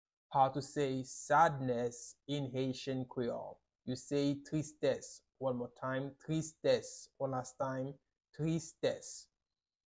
How to say "Sadness" in Haitian Creole -"Tristès" pronunciation by a private Haitian Creole teacher
“Tristès” Pronunciation in Haitian Creole by a native Haitian can be heard in the audio here or in the video below:
How-to-say-Sadness-in-Haitian-Creole-Tristes-pronunciation-by-a-private-Haitian-Creole-teacher.mp3